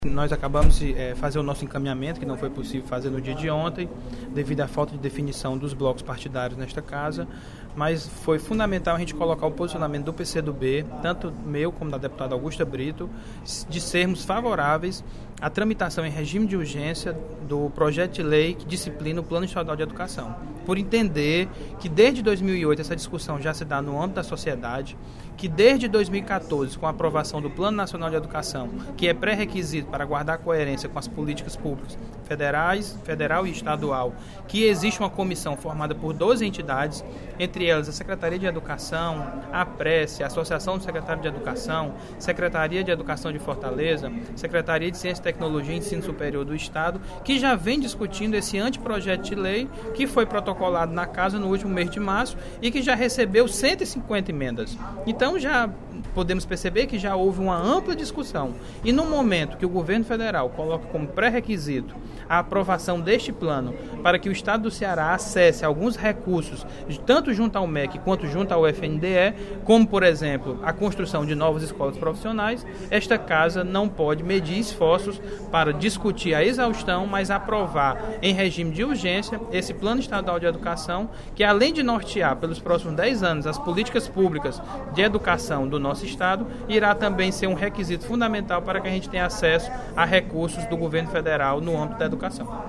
O deputado George Valentim (PCdoB) destacou, durante o primeiro expediente da sessão plenária da Assembleia Legislativa desta sexta-feira (29/04), a importância da tramitação em regime de urgência do projeto de lei que institui o Plano Estadual de Educação.